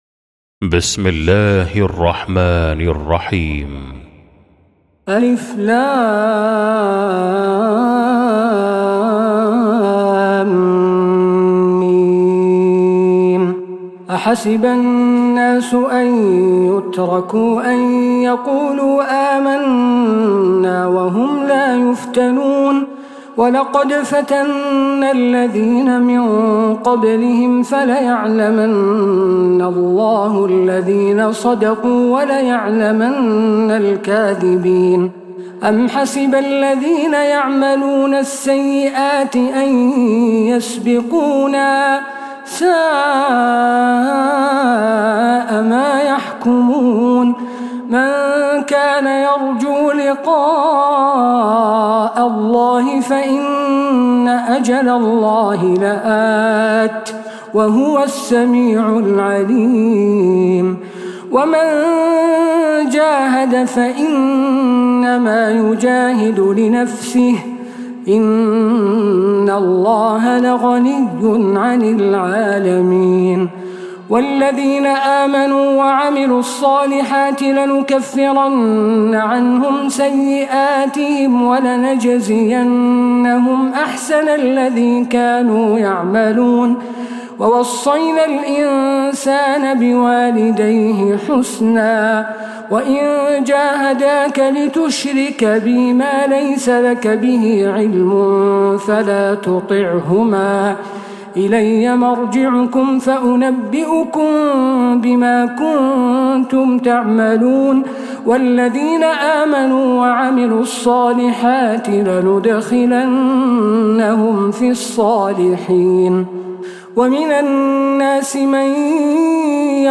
سورة العنكبوت | Surah Al-Ankabut > مصحف تراويح الحرم النبوي عام 1446هـ > المصحف - تلاوات الحرمين